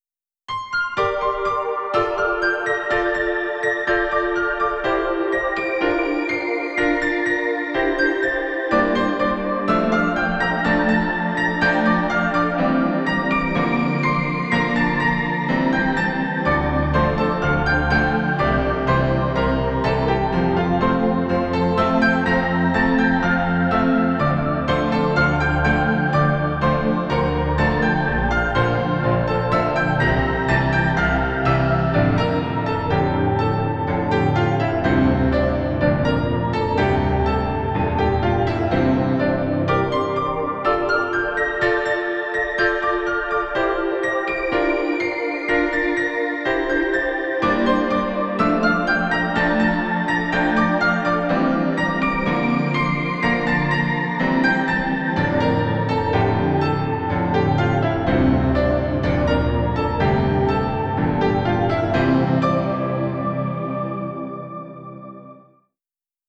music / PIANO D-G